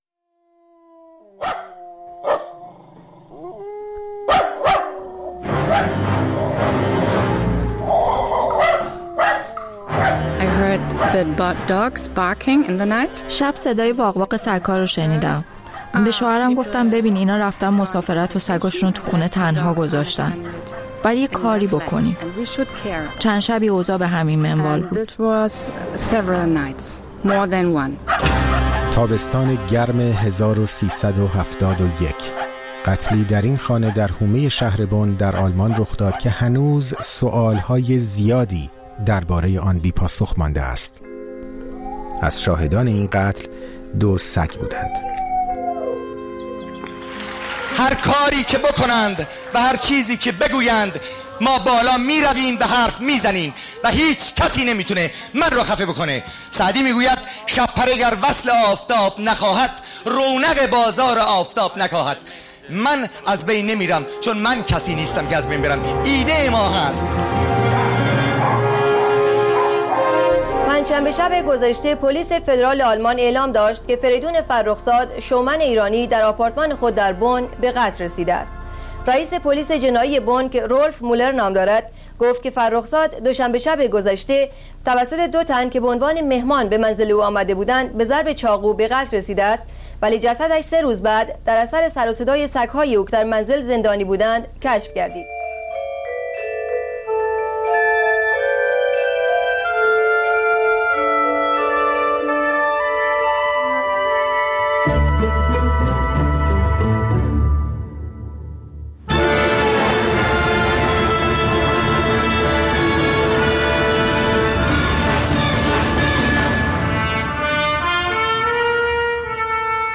«شرقی غمگین»؛ مستند تحقیقی رادیوفردا درباره قتل فریدون فرخزاد، قسمت اول